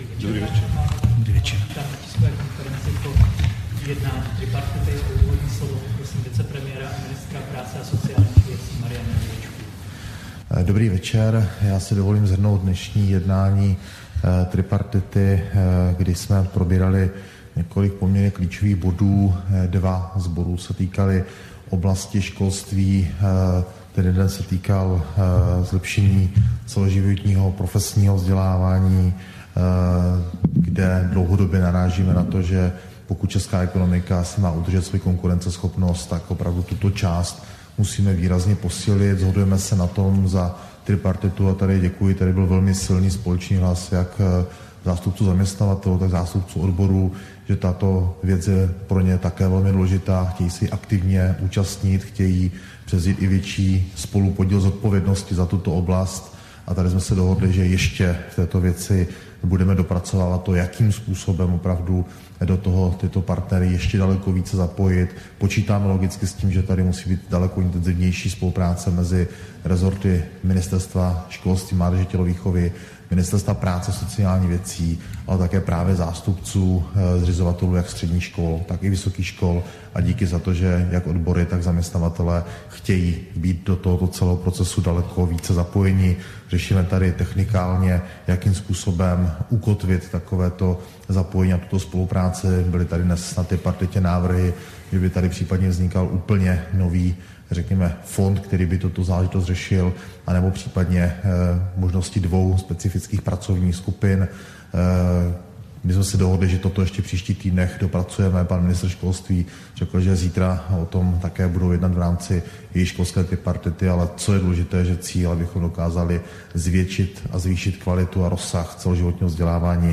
Tisková konference po jednání tripartity, 4. prosince 2023